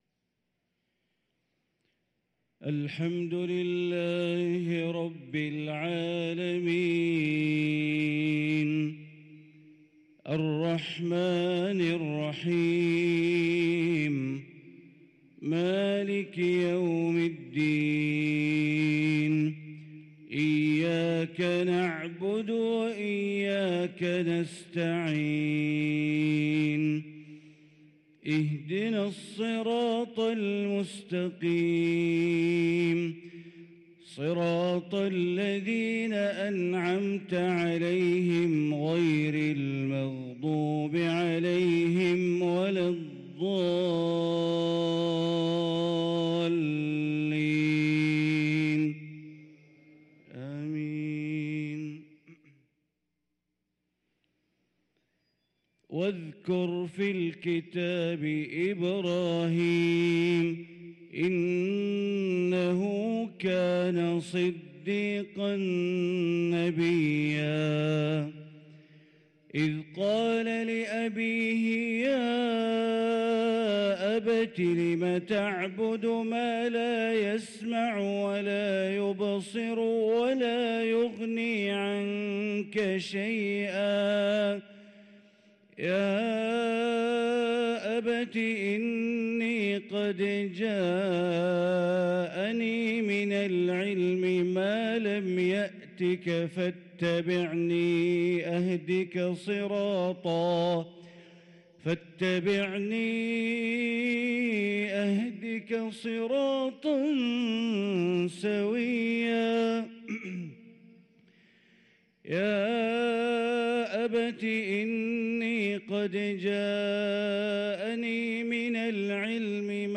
صلاة الفجر للقارئ بندر بليلة 6 ربيع الآخر 1444 هـ